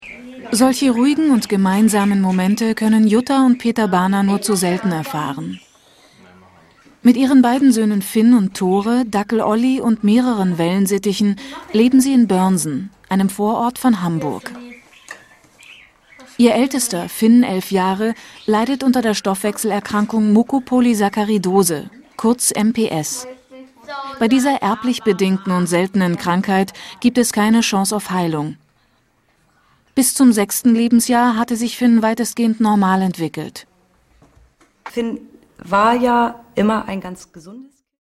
deutsche Sprecherin,Hörspiel,Computerspiele,Werbung,Voice over,Imagefilm,Hörbuch variabel von rauchig dunkel bis spritzig frisch, sexy.
Sprechprobe: eLearning (Muttersprache):